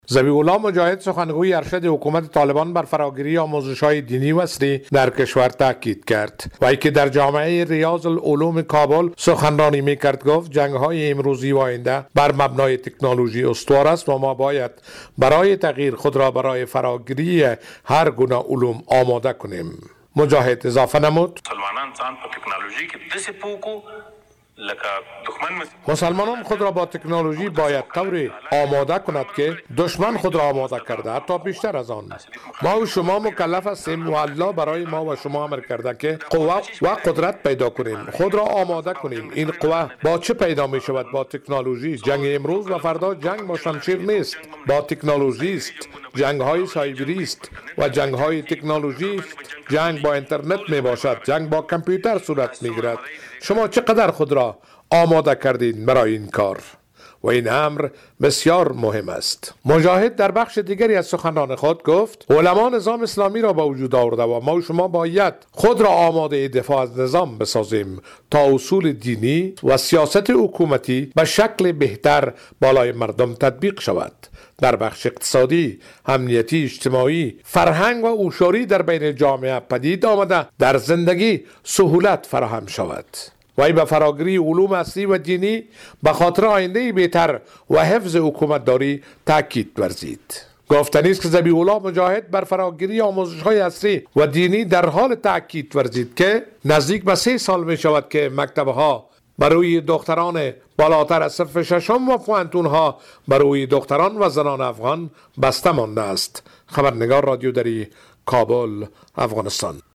سخنگوی ارشد طالبان در جریان سخنرانی در یک مدرسه دینی ضمن تاکید بر حمایت از فراگیری دانش می گوید که علوم طبیعی و علوم شرعی برای جامعه اسلامی ضروری است.